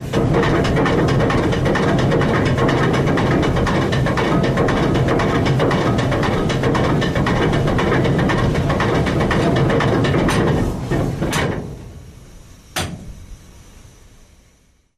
Anchor is dropped and retrieved. Boat, Anchor Engine, Steamship